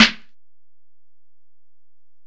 Snare (1).wav